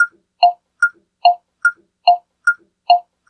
Round 2 Beep Loop.wav